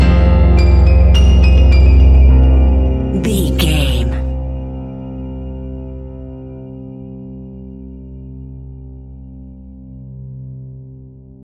In-crescendo
Aeolian/Minor
ominous
dark
haunting
eerie
horror music
Horror Pads
horror piano
Horror Synths